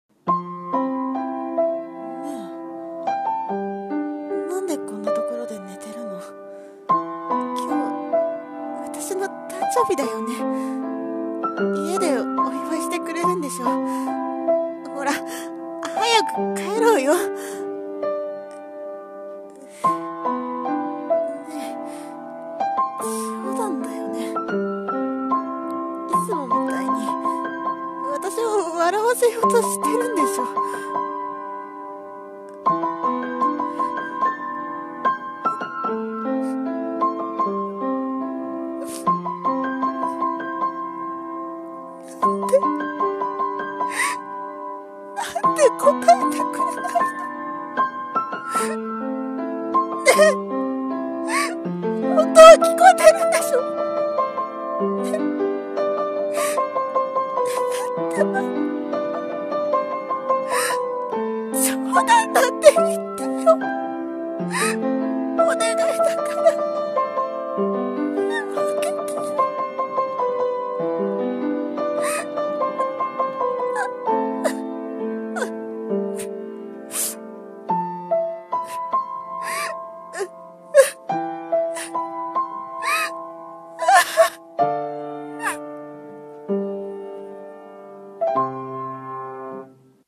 大切な人の死【演技力】 朗読者